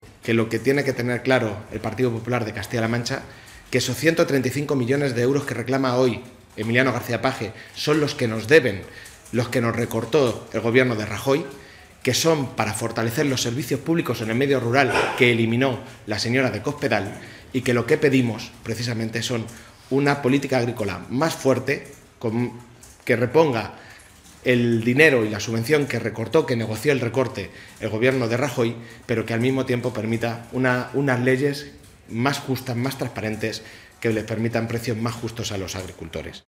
Sergio Gutiérrez, diputado del PSOE por Toledo en el Congreso y secretario de Organización del PSOE CLM.
En rueda de prensa, el secretario de Organización del PSOE regional, Sergio Gutiérrez, ha asegurado que la propuesta que han hecho los ‘populares’ y que se debatirá este jueves en el Parlamento autonómico es «la ocurrencia de la semana, como consecuencia de la protesta de la semana», lo que, a su parecer, demuestra que Núñez es «un líder que no tiene proyecto político y, en función de cómo venga el viento, así pone la vela».